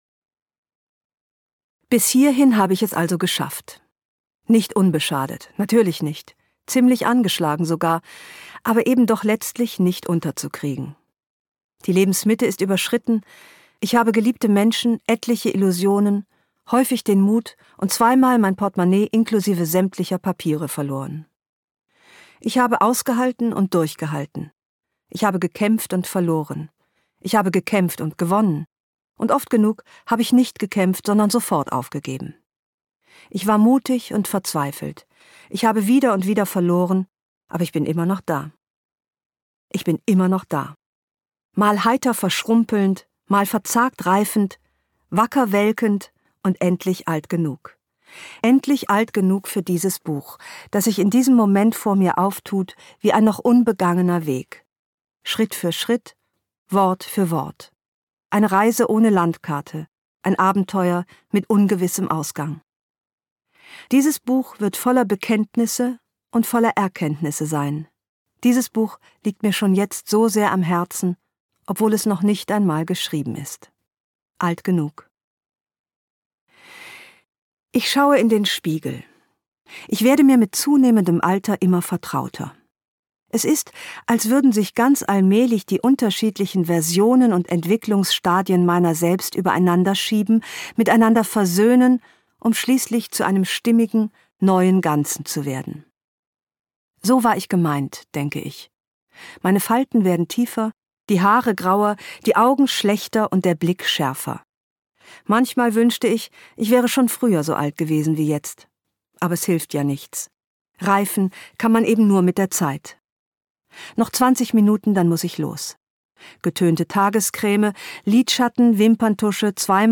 Details zum Hörbuch
Sprecher Ildikó von Kürthy